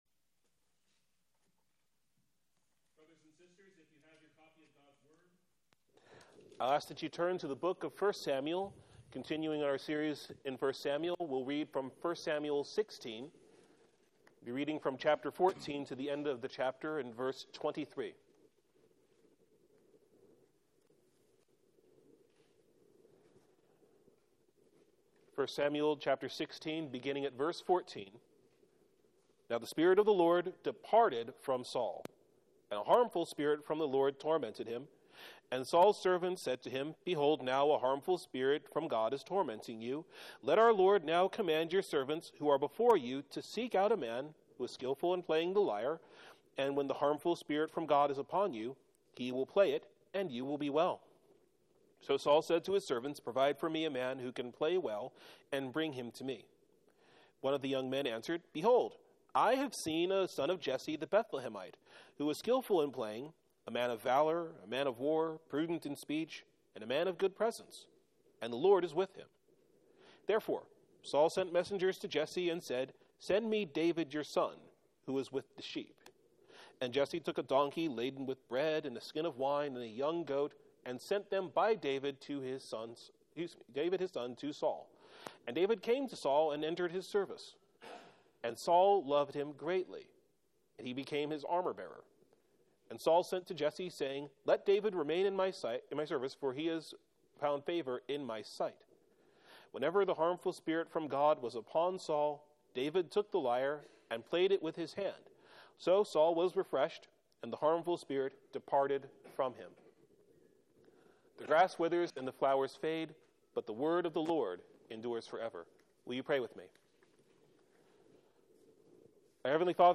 Sermon Text: 1 Samuel 16:14-23 Theme: As Saul declined as the king of Israel, God provided for the eventual rise of David, the king whom the Lord was with.